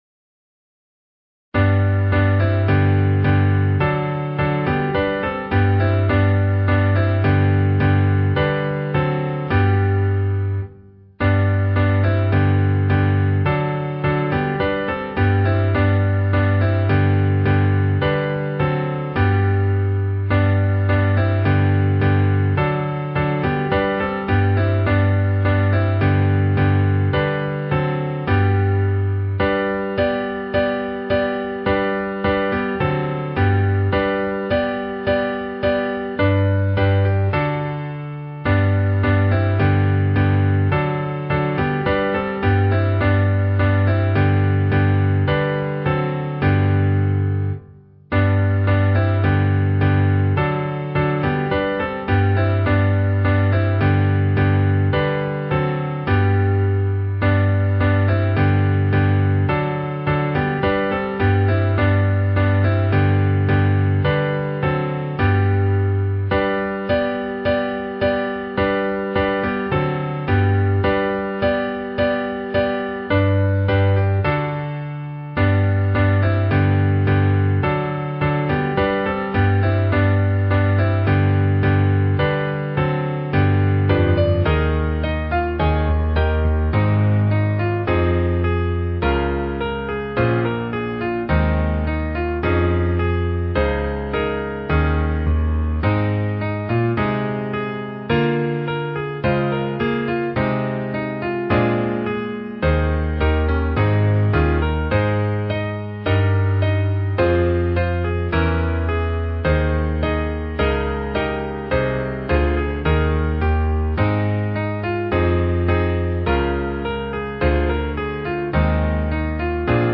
Key: A♭